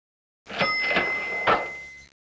1 channel
pokladna.wav